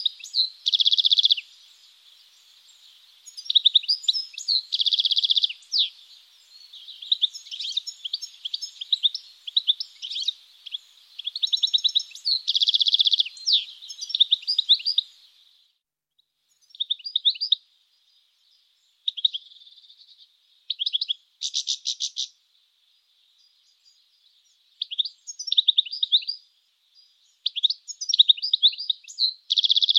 Chardonneret élégant photos - Mes Zoazos
chardonneret-elegant.mp3